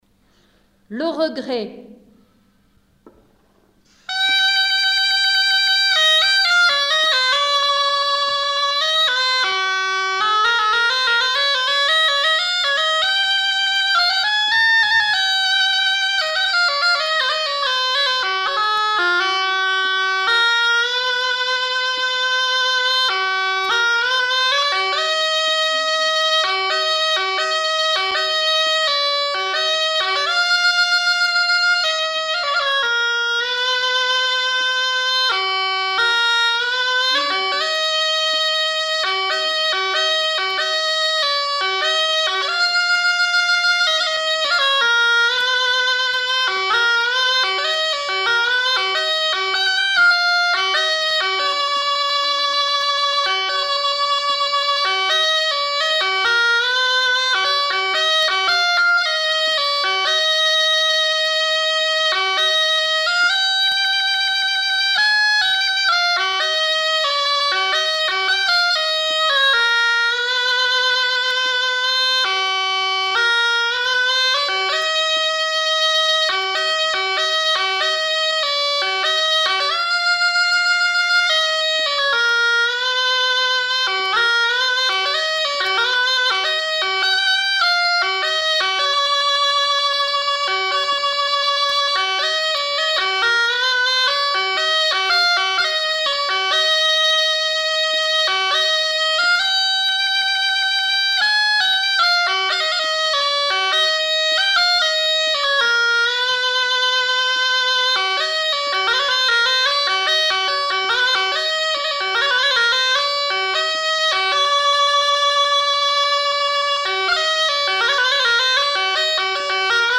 Lieu : Marvejols
Genre : morceau instrumental
Instrument de musique : cabrette